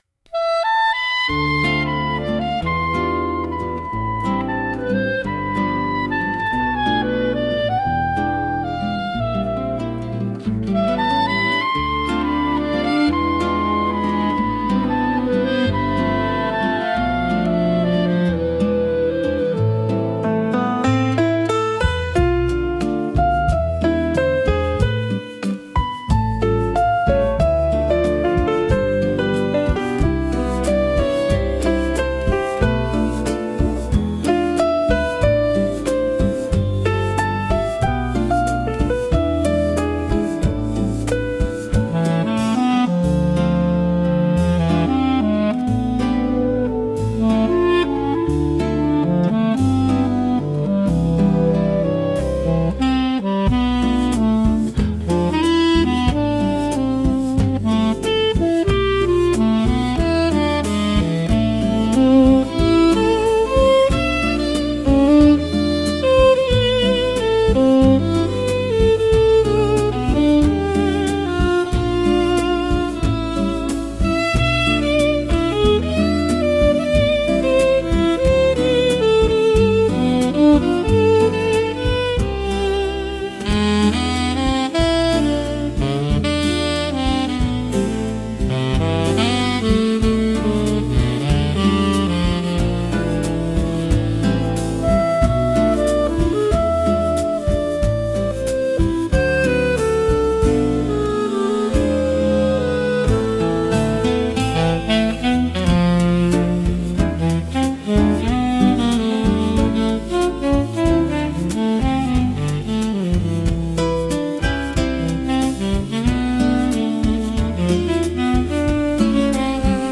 música e arranjo: IA) (Instrumental)